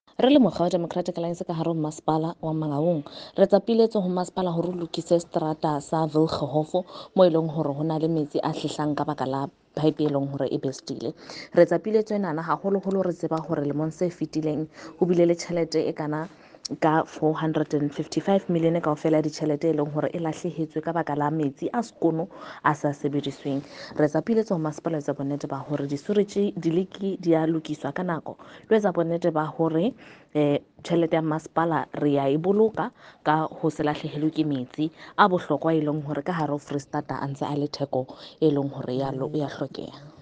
Sesotho by Karabo Khakhau MP.